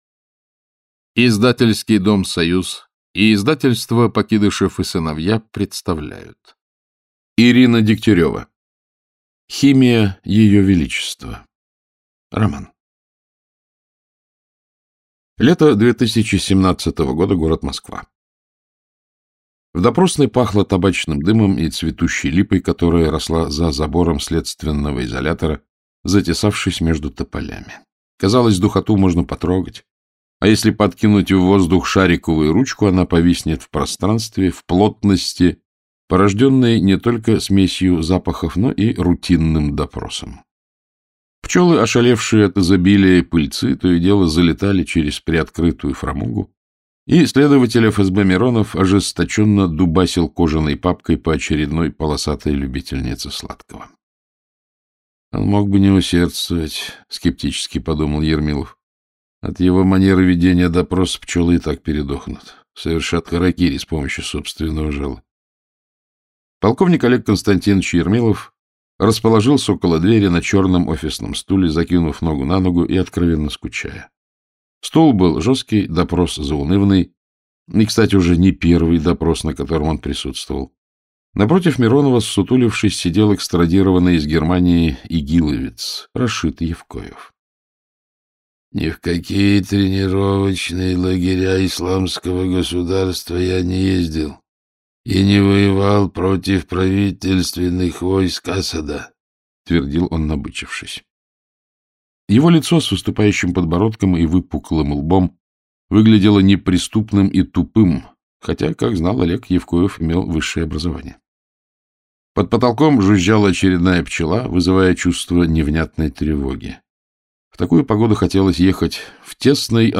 Aудиокнига Химия Ее Величества Автор Ирина Дегтярева Читает аудиокнигу Александр Клюквин. Прослушать и бесплатно скачать фрагмент аудиокниги